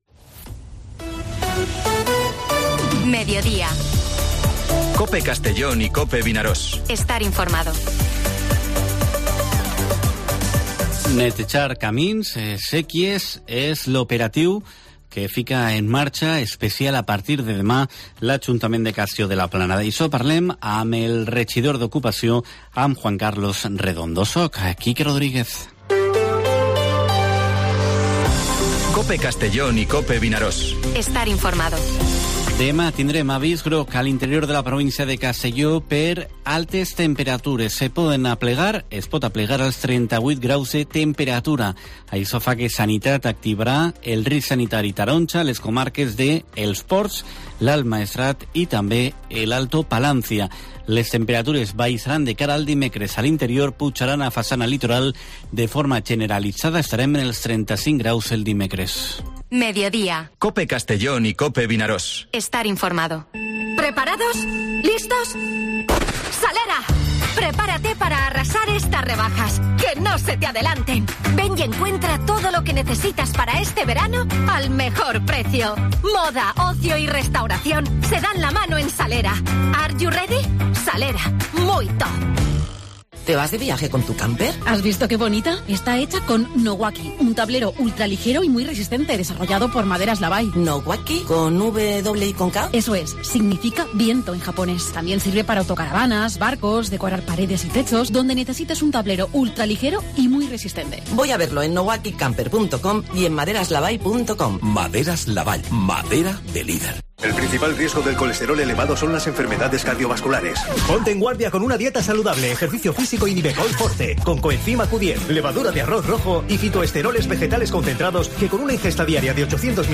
La entrevista con el edil del ayuntamiento de Castellón Juan Carlos Redondo protagoniza el "Mediodía COPE Castellón" de hoy.